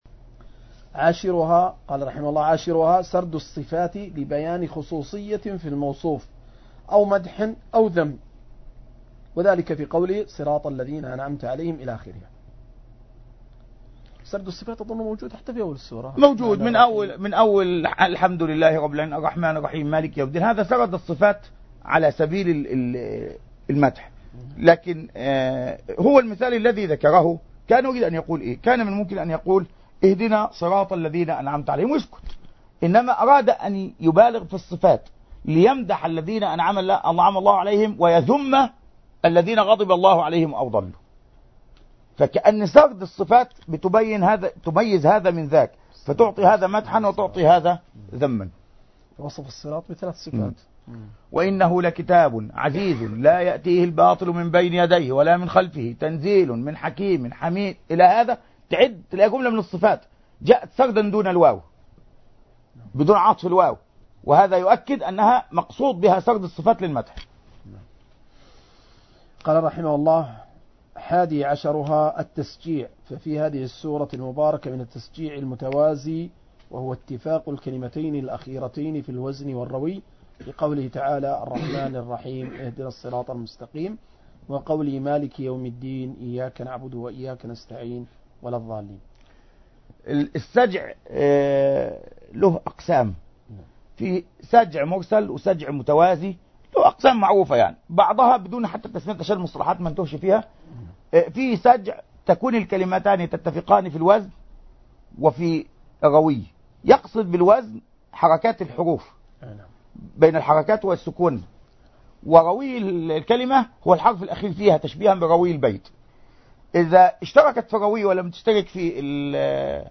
مجلس في شرح أنواع البلاغة في سورة الفاتحة من تفسير العلامة الدوسري رحمه الله